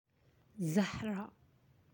(zahra)
How to say flower in Arabic